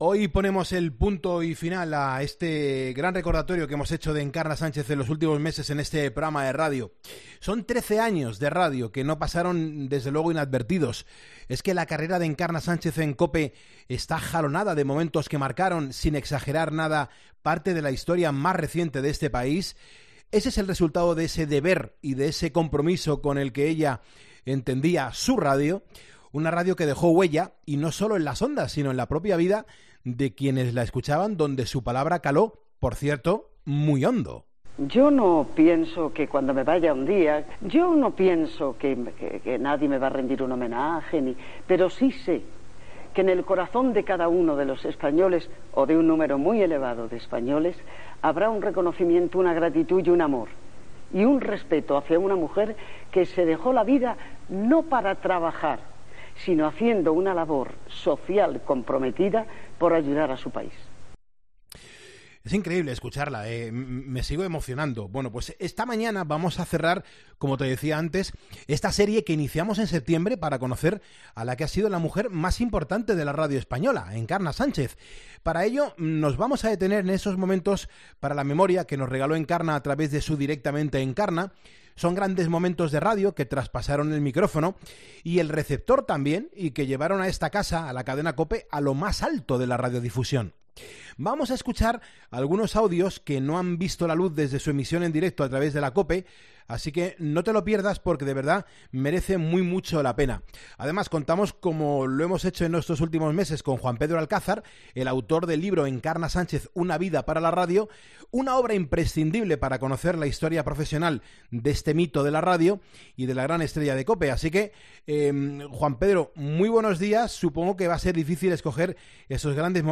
Vamos a escuchar algunos audios que no han visto la luz desde su emisión en directo a través de la COPE, así que no os lo perdáis porque de verdad que merece mucho la pena.